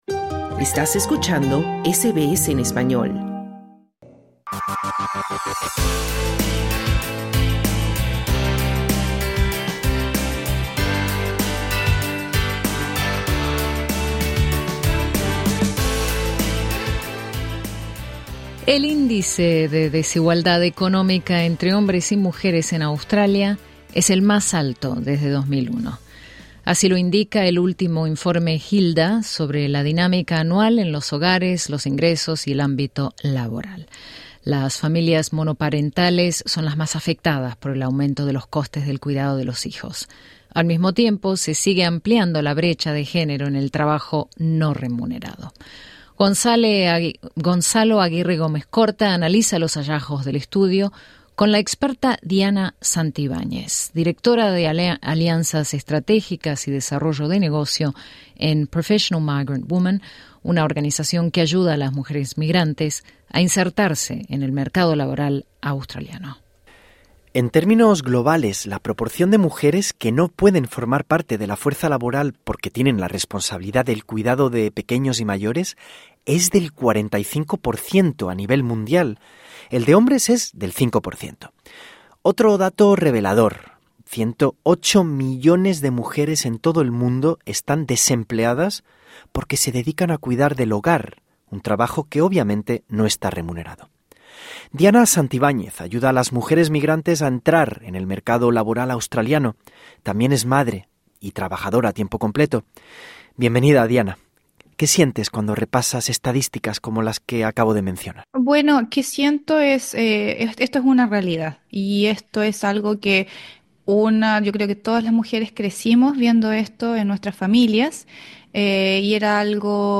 Sinópsis En esta conversación